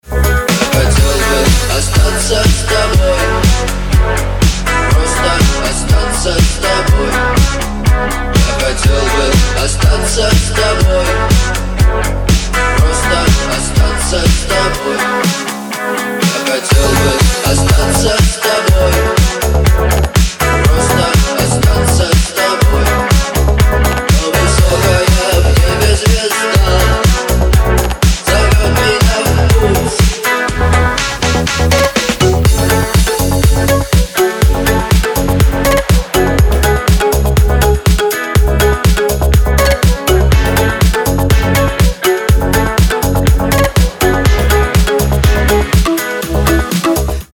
• Качество: 320, Stereo
dance
club
house